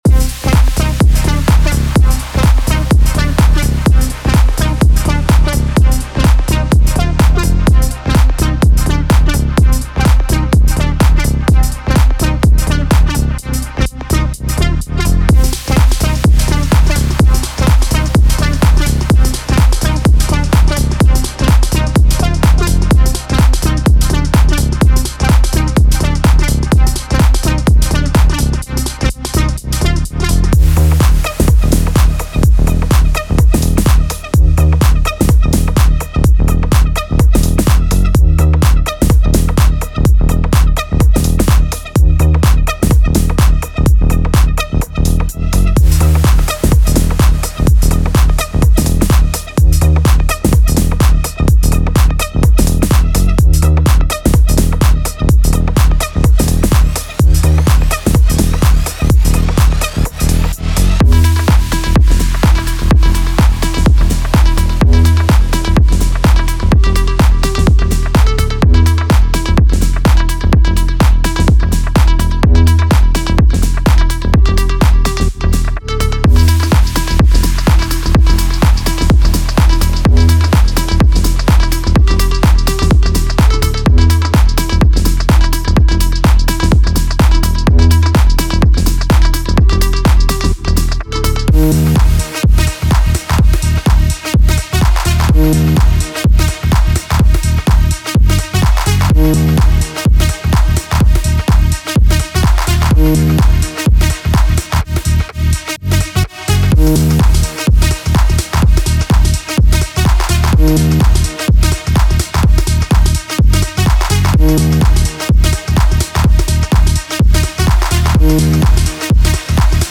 This expertly crafted loop pack is designed to elevate your tech house productions, delivering a powerful blend of deep basslines, driving drums, and cutting-edge synths.
25 Bass Loops: Groove your tracks into overdrive with these punchy and resonant bass loops.
From steady, driving rhythms to intricate percussive patterns, these loops will give your tracks the perfect tech house groove.
5 FX Loops: Add excitement and movement to your transitions with these atmospheric and impactful FX loops.
From dark and brooding to bright and uplifting, these loops provide a wide range of sonic textures to inspire your creativity.